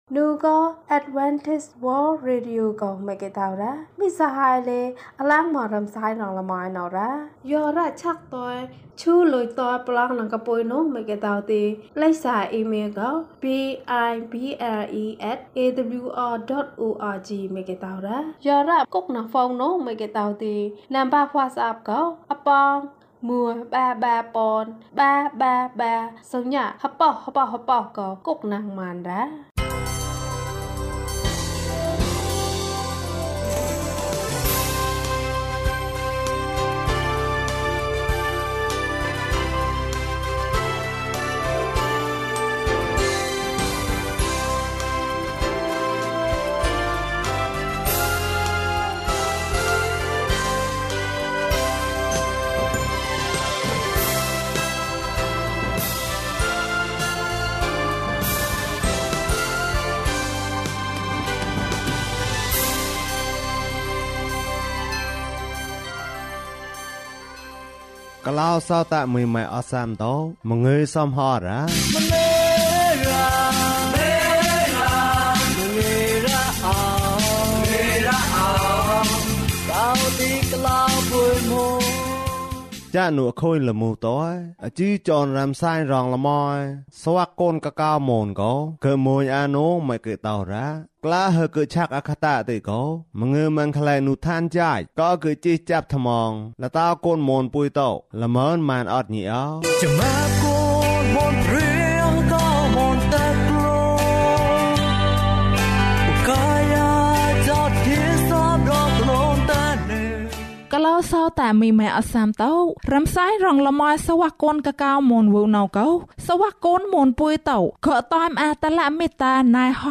ယေရှုနှင့်အတူ လမ်းလျှောက်ခြင်း။ ကျန်းမာခြင်းအကြောင်းအရာ။ ဓမ္မသီချင်း။ တရားဒေသနာ။